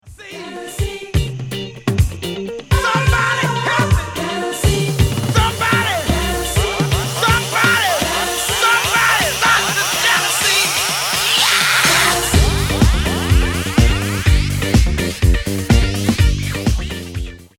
Boosting the highs. This trick is similar to cutting the mids and bass in that it creates an unsettling, discomforting sound. Pushing the treble works well in conjunction with cutting the lows just before a climax:
effects_tricks-boosting_highs.mp3